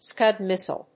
/skˈʌd‐(米国英語)/
アクセントScúd míssile 発音記号・読み方/skˈʌd‐/